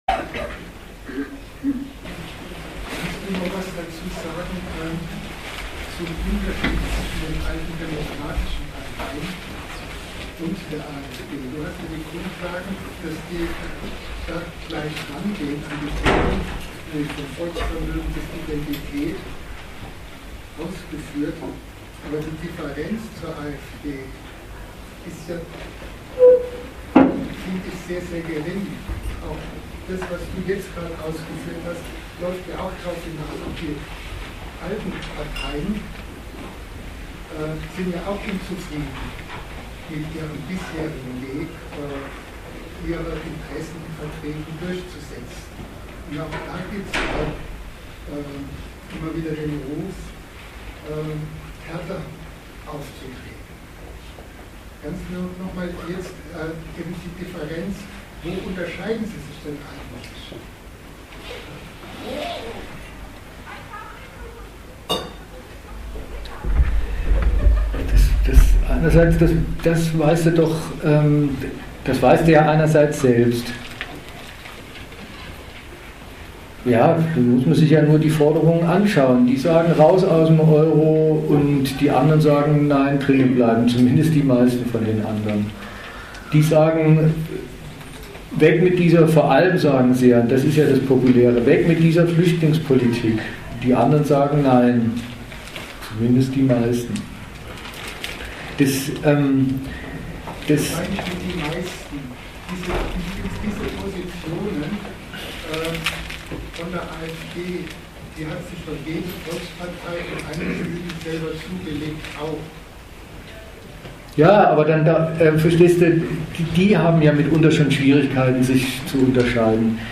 Datum 07.12.2016 Ort München Themenbereich Volk, Nation und Moral Veranstalter Arbeitskreis Gegenargumente Dozent Gastreferenten der Zeitschrift GegenStandpunkt „Wir sind das Volk!“